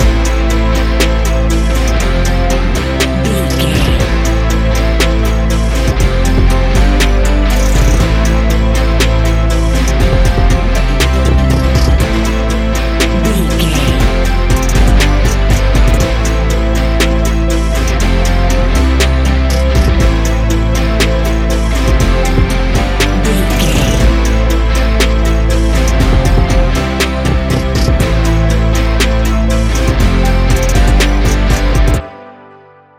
Ionian/Major
A♭
ambient
electronic
new age
chill out
downtempo
pads